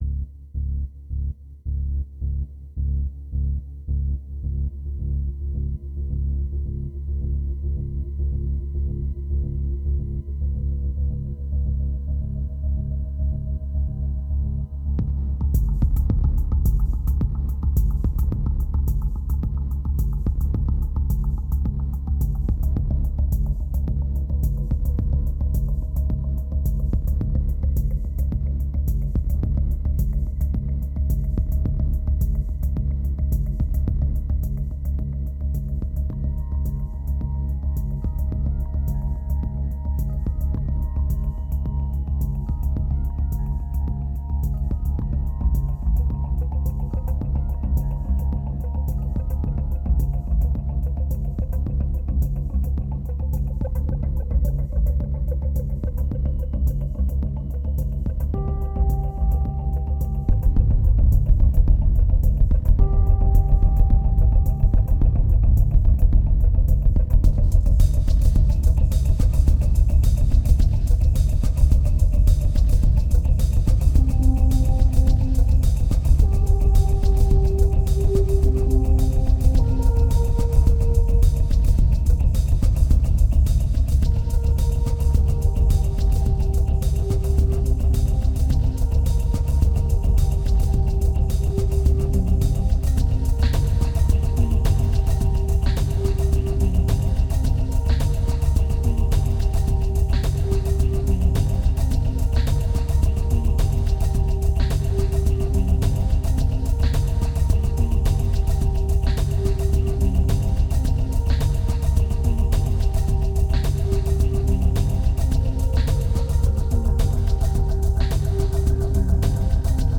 2083📈 - 23%🤔 - 108BPM🔊 - 2010-12-05📅 - -49🌟